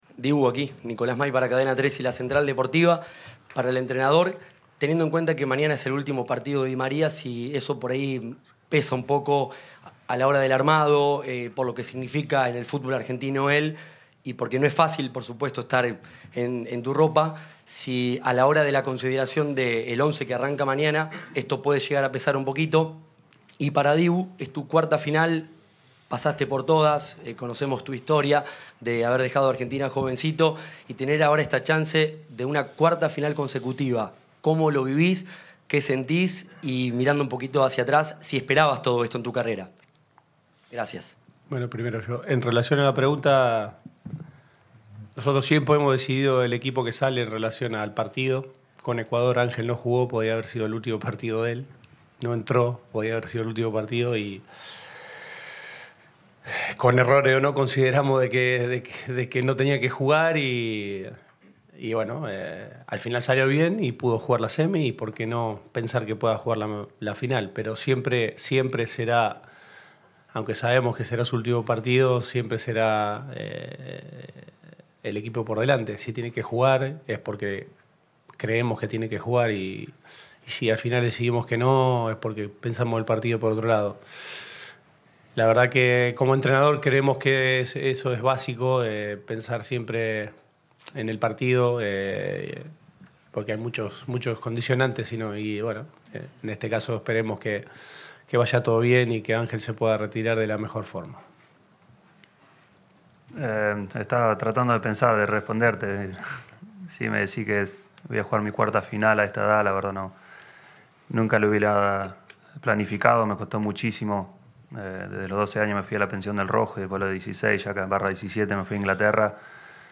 El DT de la selección argentina dialogó ante la prensa antes del duelo decisivo de este domingo por la noche ante Colombia.
Lionel Scaloni en conferencia de prensa.